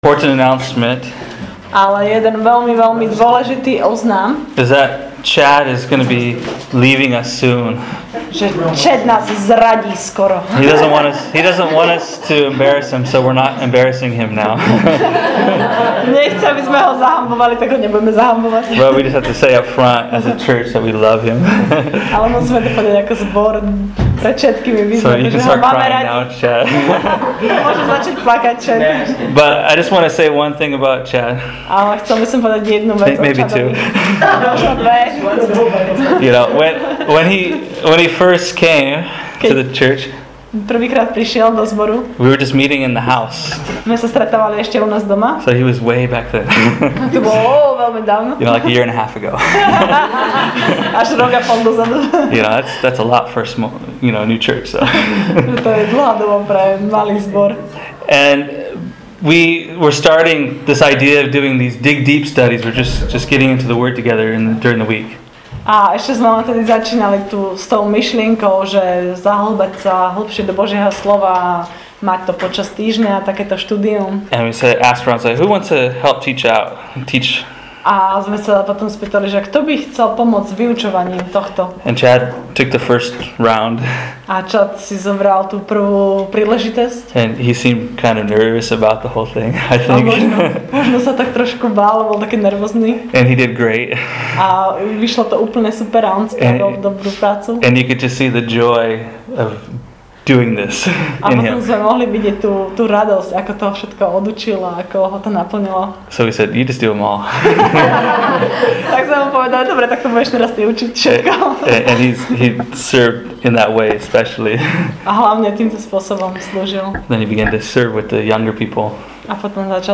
We often exchange the love of God for our self-defeating lies about God. Yet we are challenged by God to be brave and courageous and simply wait on Him to show up and guide us to deep, true, real life. Dive deep into this teaching from Exodus 24-32.